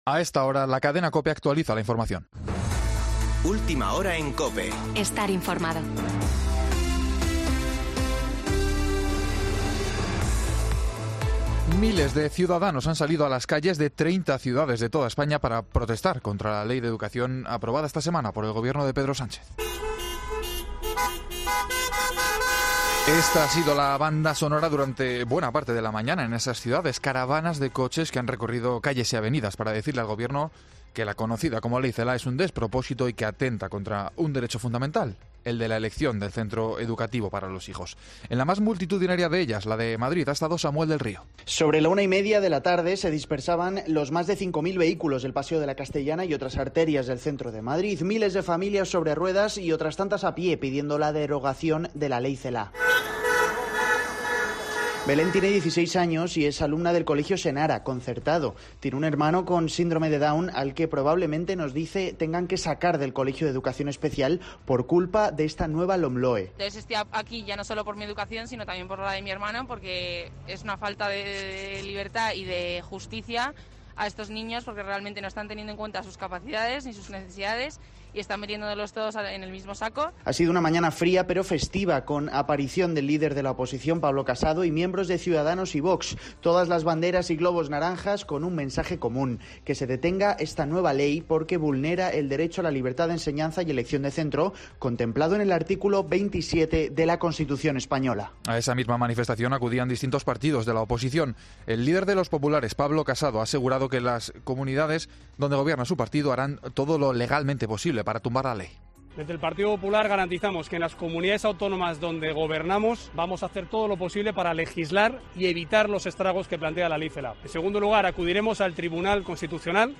Boletín de noticias COPE del 22 de noviembre de 2020 a las 19.00 horas